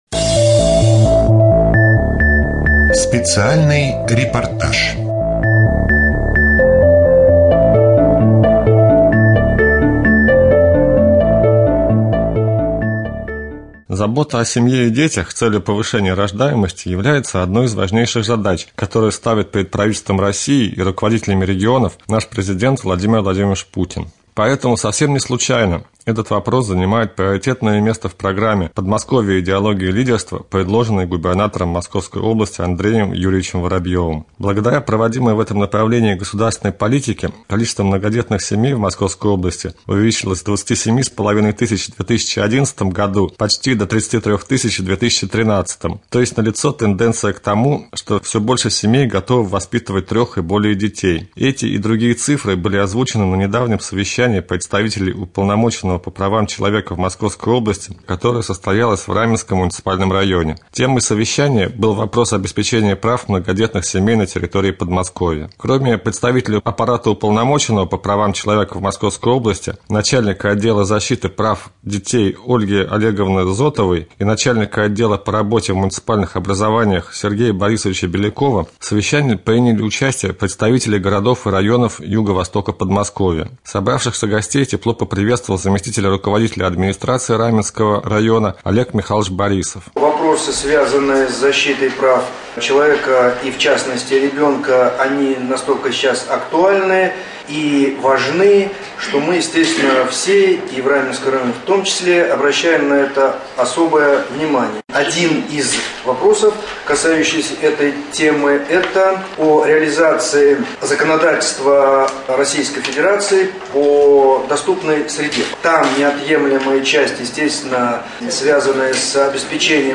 03.04.2014г. в эфире раменского радио - РамМедиа - Раменский муниципальный округ - Раменское